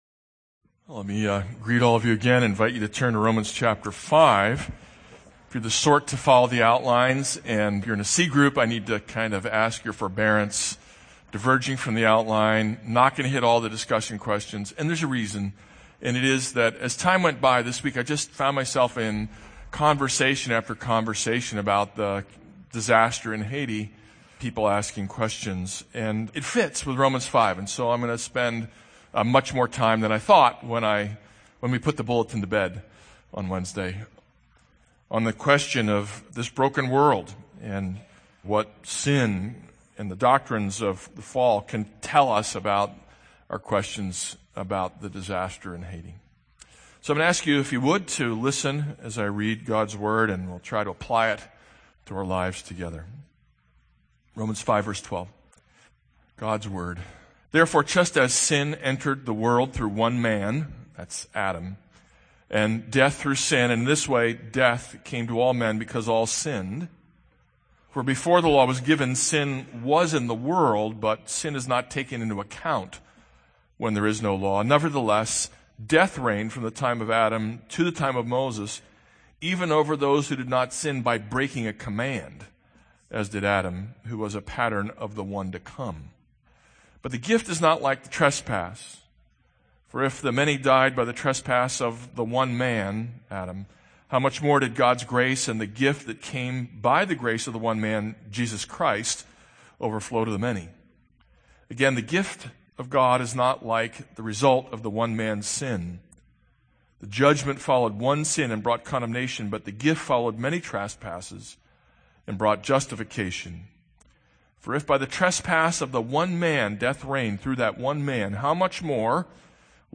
This is a sermon on Romans 5:12-21 - two humanities.